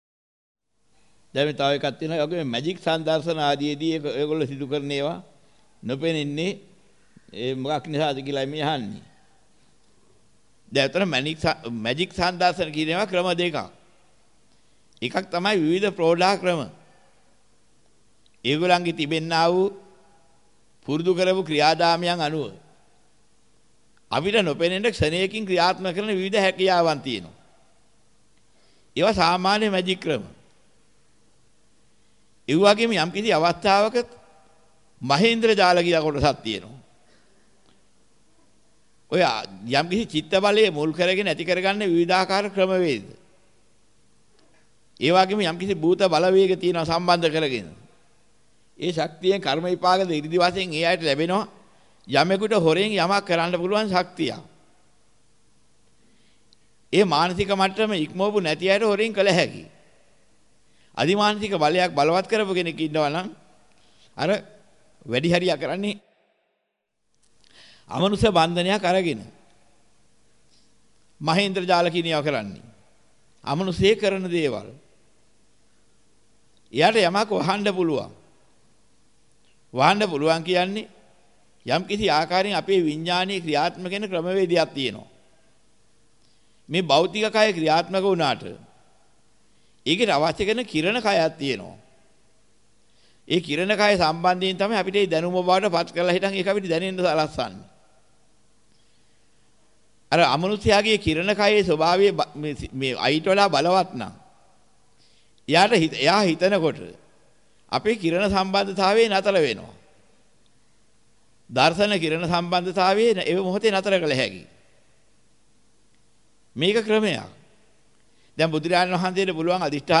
වෙනත් බ්‍රව්සරයක් භාවිතා කරන්නැයි යෝජනා කර සිටිමු 07:01 10 fast_rewind 10 fast_forward share බෙදාගන්න මෙම දේශනය පසුව සවන් දීමට අවැසි නම් මෙතැනින් බාගත කරන්න  (5 MB)